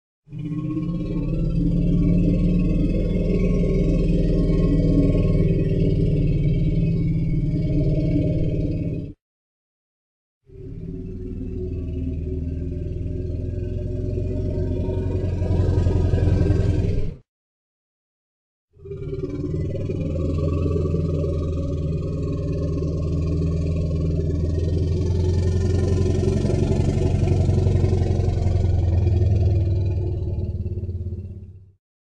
Звуки мамонта
Дыхание древнего мамонта